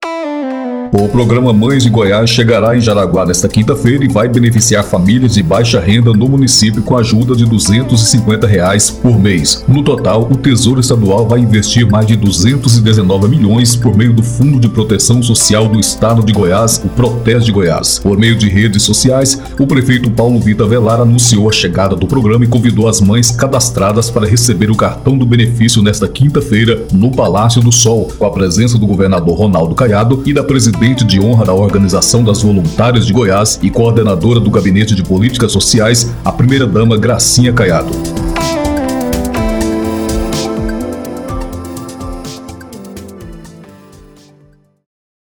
Matéria em áudio